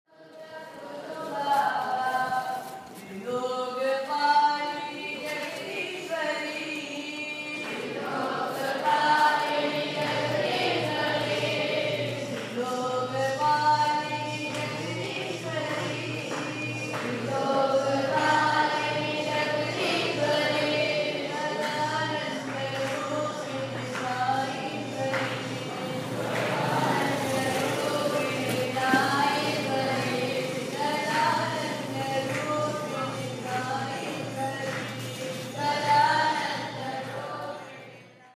3/11 ヒンディー教の宗教歌 (リシケシュ)
隣のビーチではしゃでいたインド人の若者たちも歌っていた。